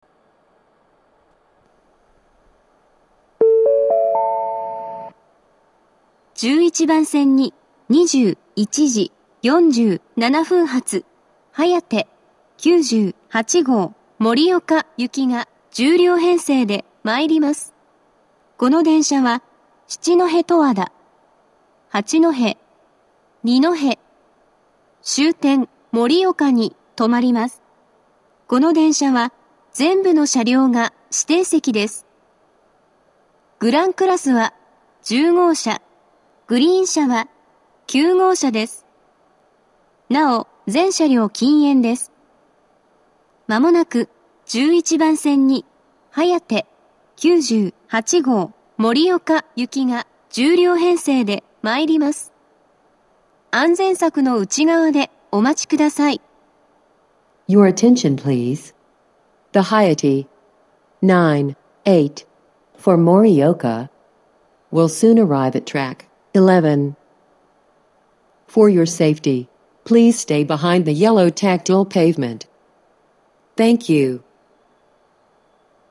１１番線接近放送
接近放送及び到着放送は「はやて９８号　盛岡行」です。